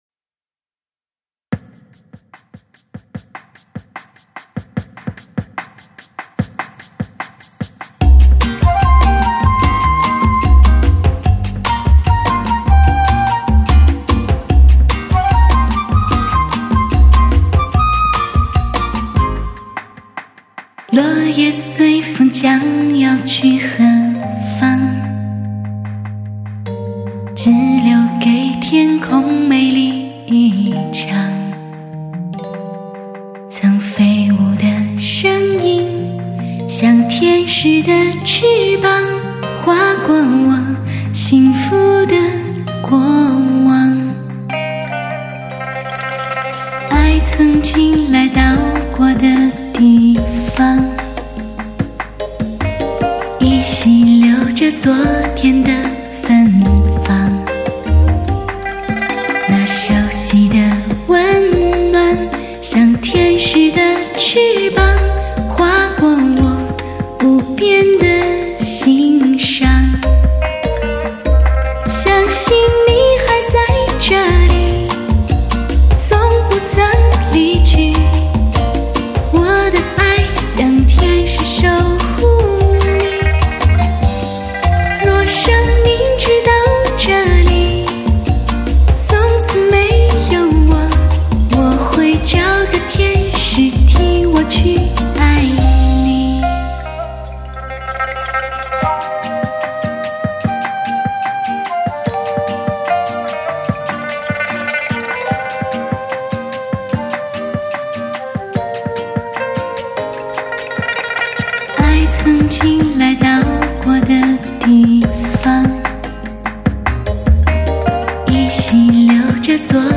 唱片类型：汽车音乐
只有专业的，才是值得信赖的， 矢志不渝，追求完美HIFI的极品靓声天碟！